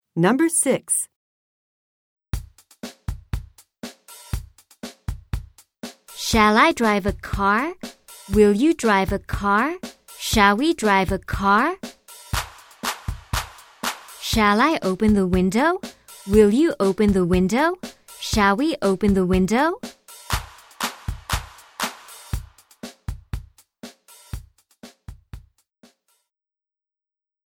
One more chants　＊宿題で聞くOne more chantとは別にカラオケなしで入れています